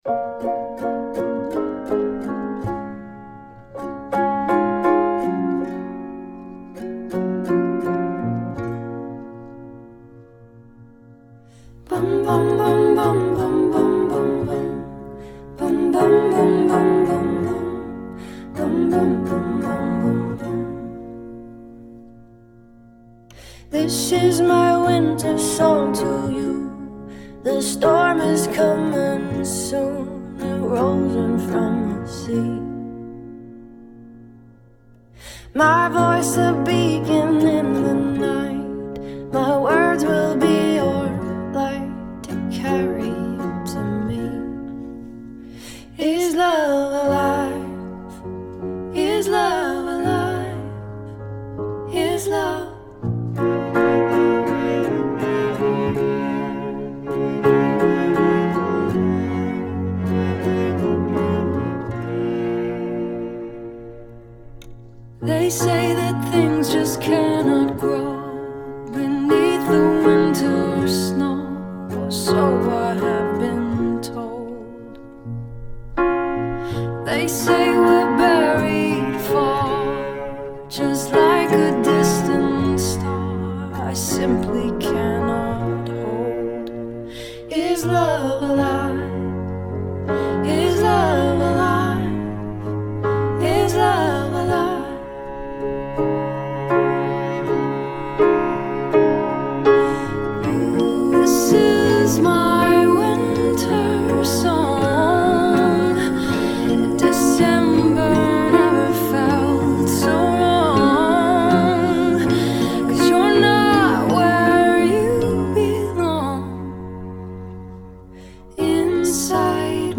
this girl has a beautiful voice